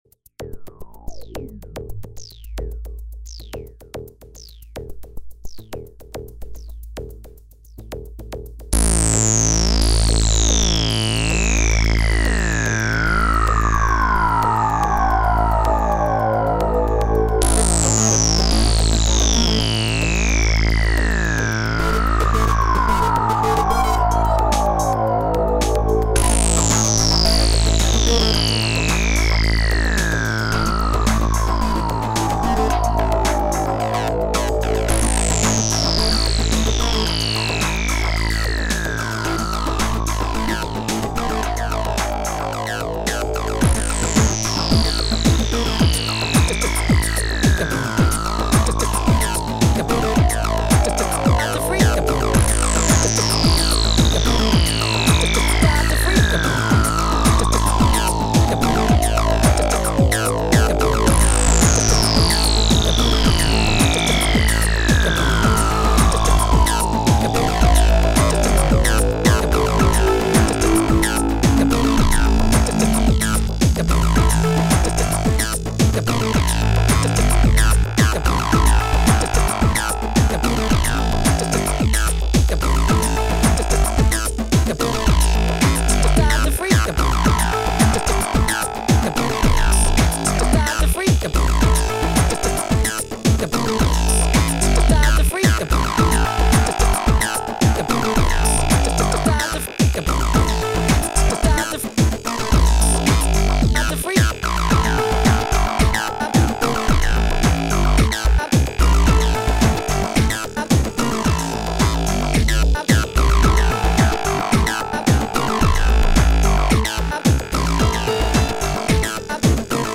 acid thingy
recorded in realtime. edited for length.